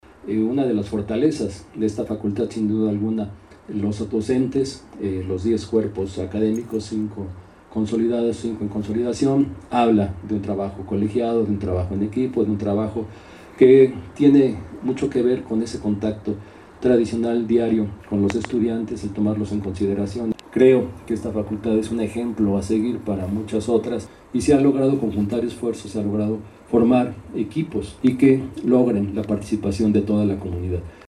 Audio-Rector-Informe-FCC.mp3